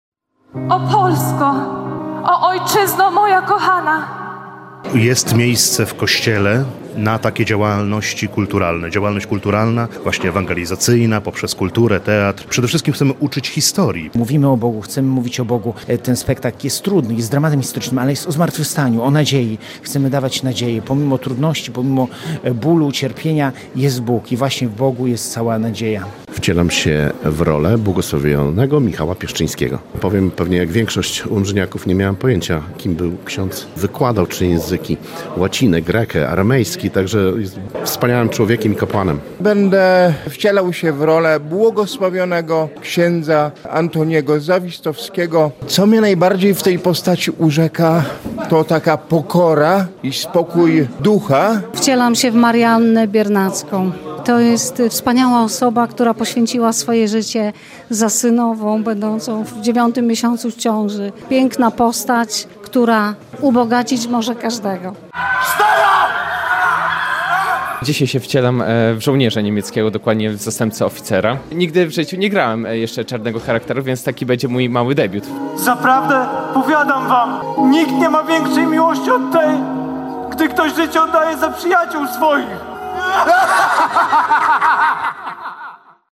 Kilkaset osób zebrało się w Sanktuarium Miłosierdzia Bożego, aby obejrzeć spektakl pt. ,,Z Miłości i dla Miłości”.